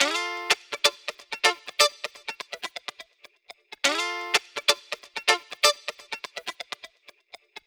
Electric Guitar 15.wav